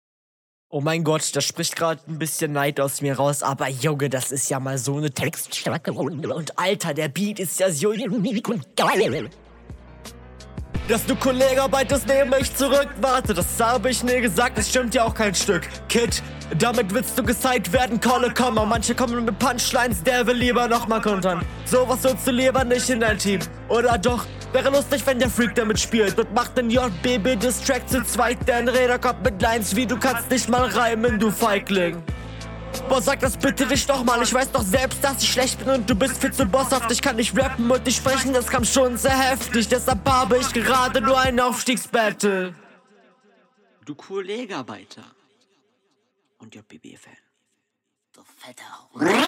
diese doubles, mach mal nicht